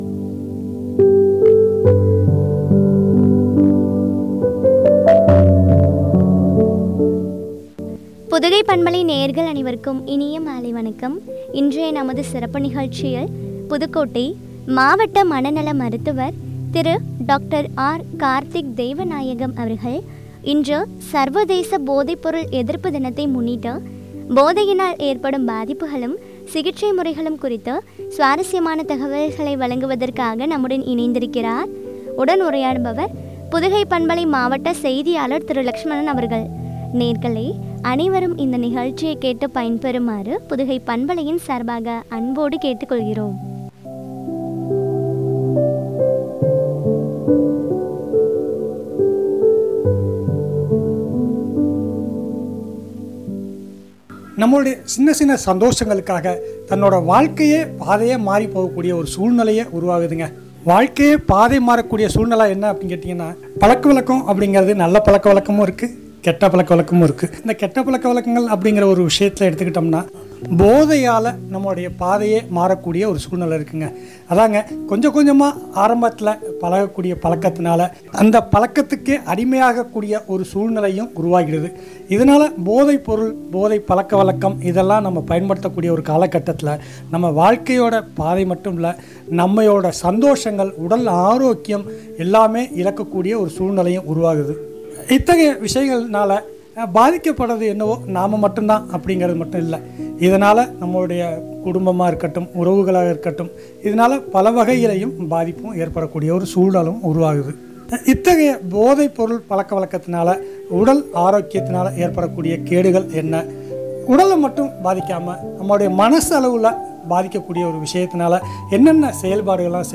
உரையாடல்